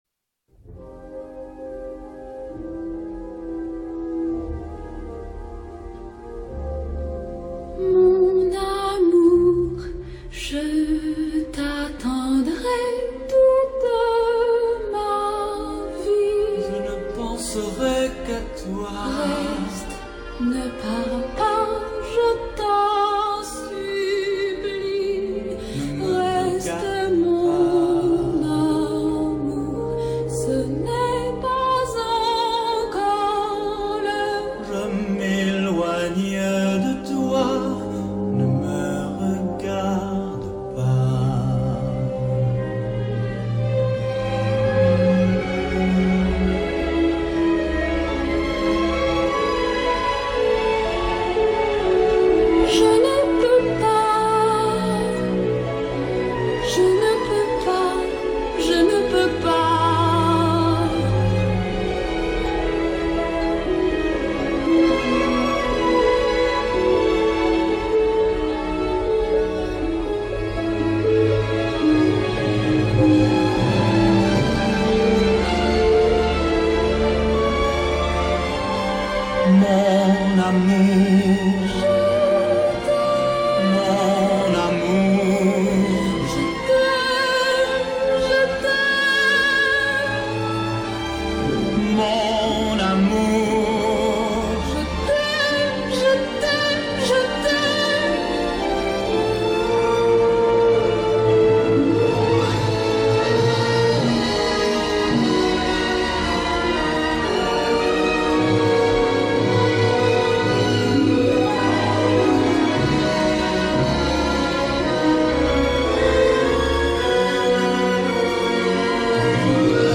のサウンドトラック版
フランス語　/　全編音楽のみで地の台詞が一切ない完全なミュージカルである。
出演者は歌の素人のため、すべて歌手による吹き替えである。